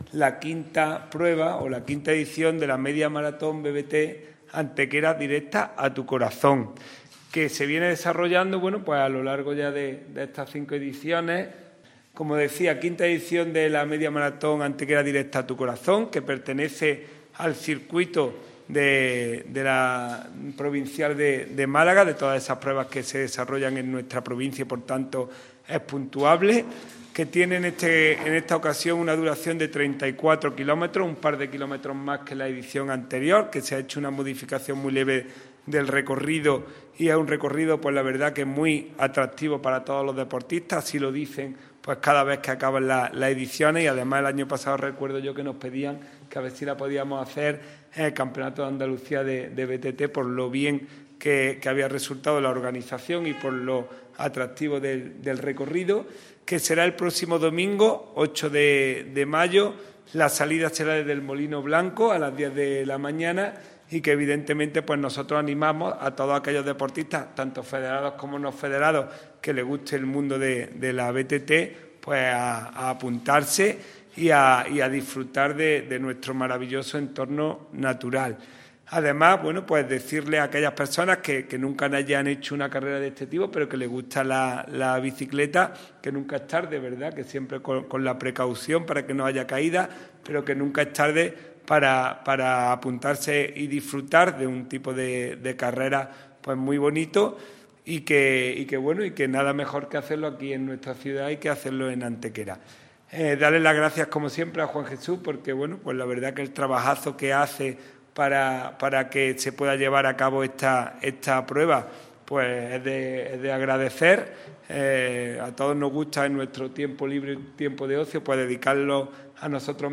han presentada en la mañana de hoy en rueda de prensa la próxima celebración de la V Media Maratón BTT de Antequera